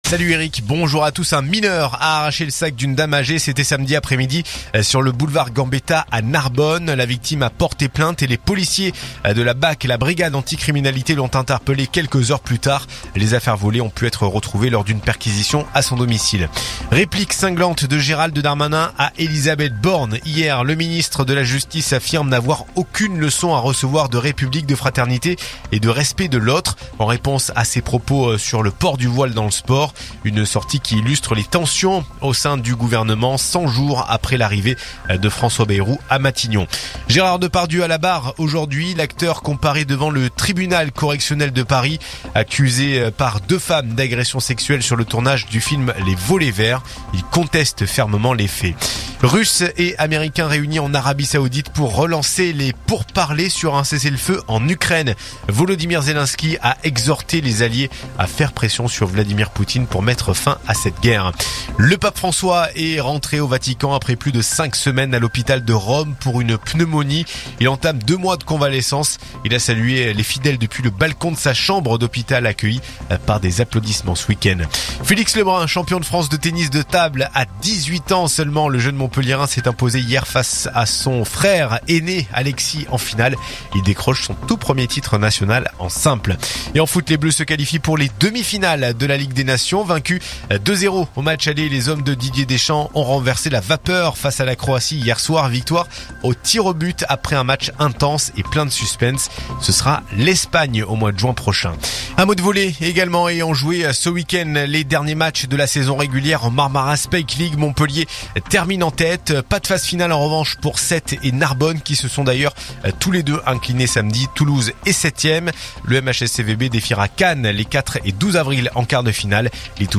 info_narbonne_toulouse_332.mp3